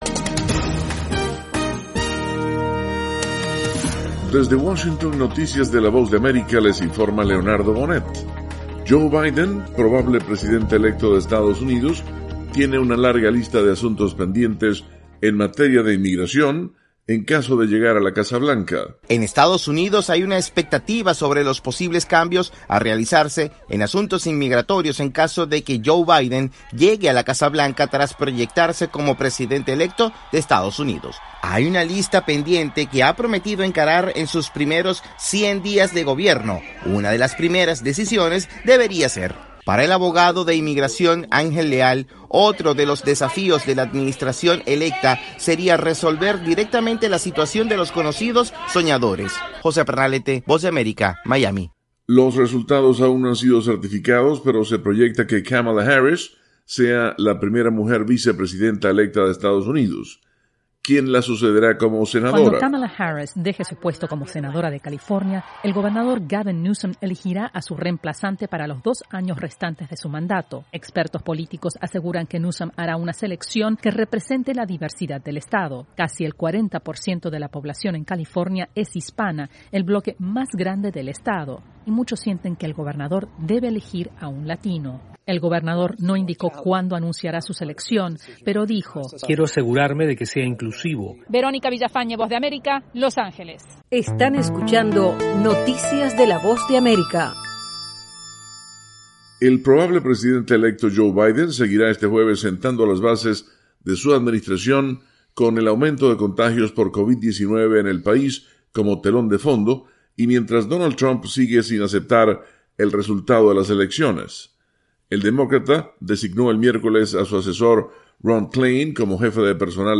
Avance Informativo - 7:00 AM